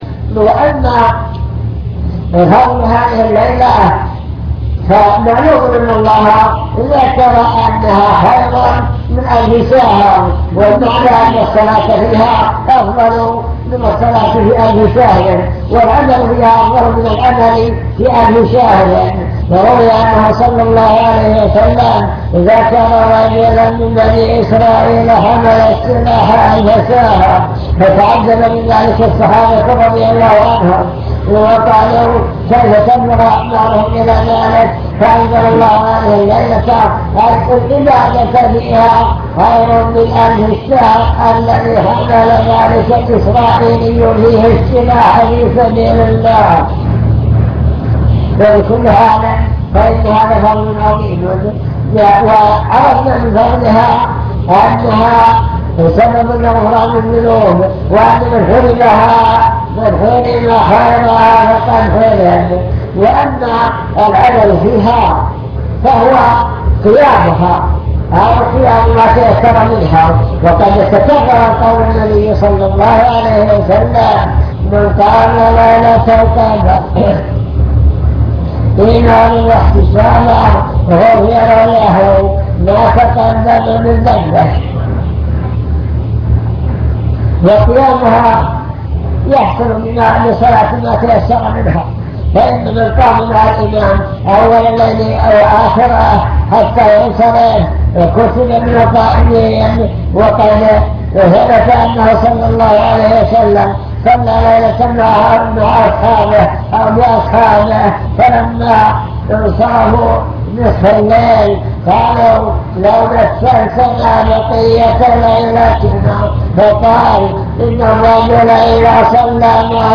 المكتبة الصوتية  تسجيلات - محاضرات ودروس  مجموعة محاضرات ودروس عن رمضان ليلة القدر